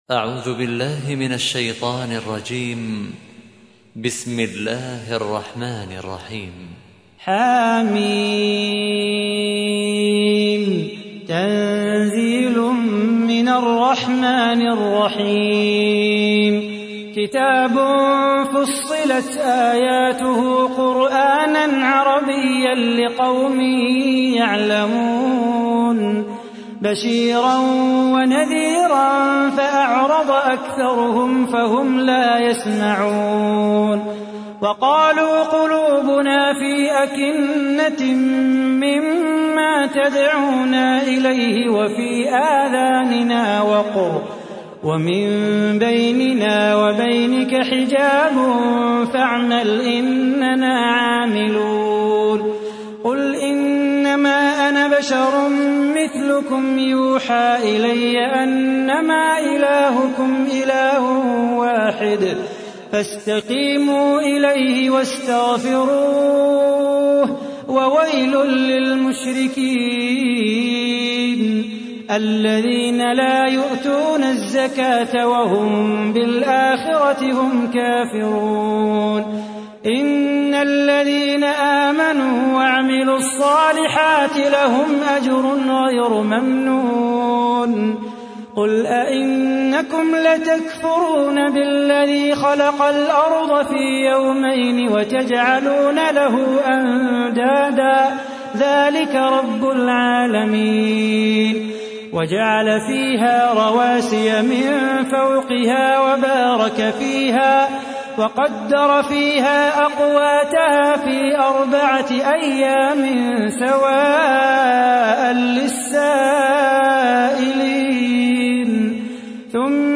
تحميل : 41. سورة فصلت / القارئ صلاح بو خاطر / القرآن الكريم / موقع يا حسين